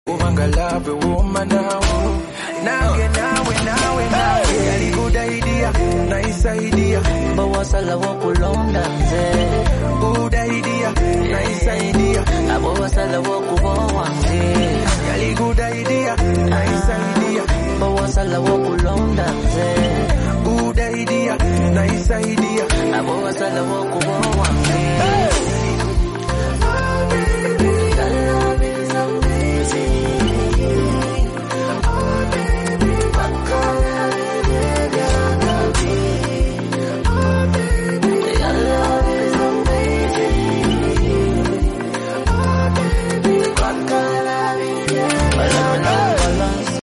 concert sound check